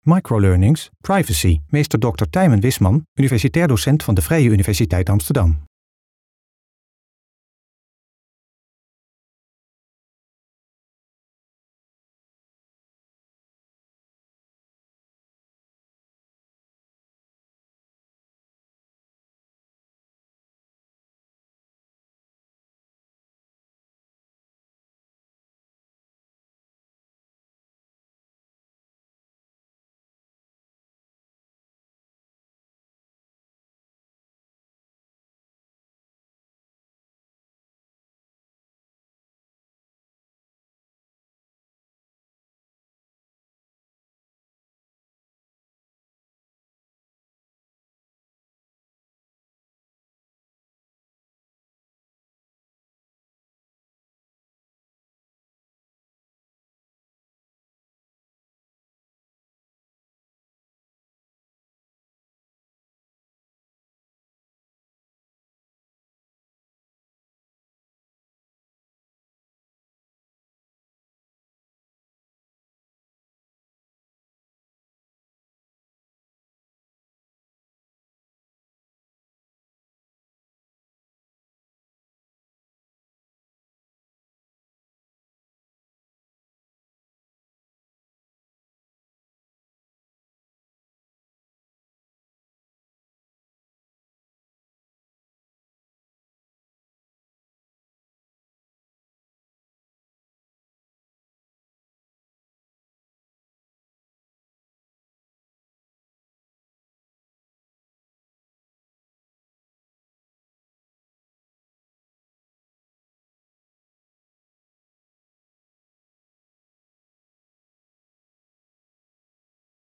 (Intro met abstracte beelden met daaronder een korte begintune.)
(Outro met abstracte beelden en korte eindtune.)